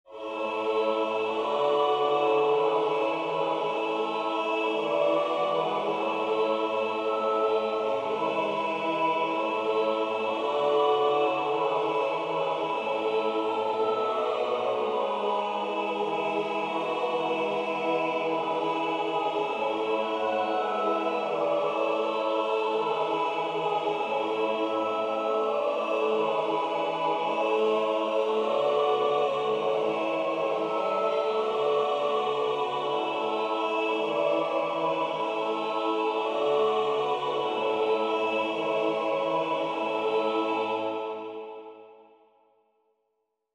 Weihnachtslied
Chor a cappella elektronisch